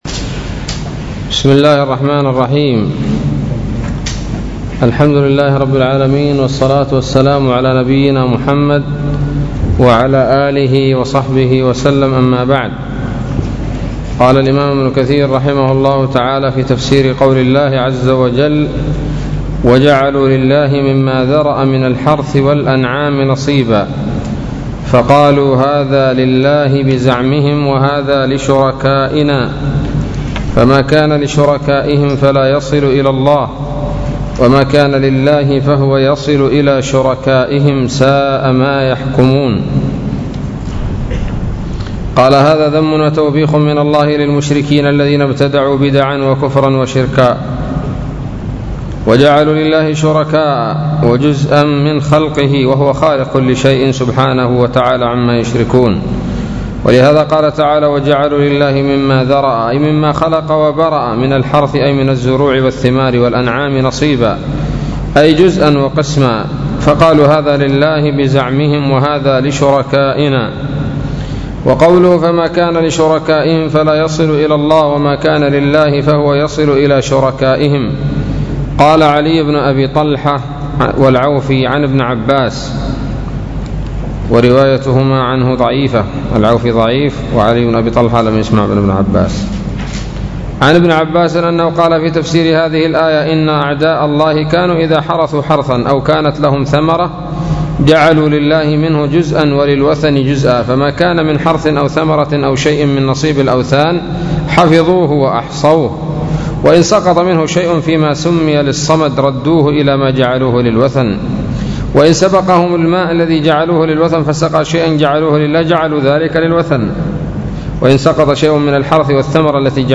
الدرس الرابع والخمسون من سورة الأنعام من تفسير ابن كثير رحمه الله تعالى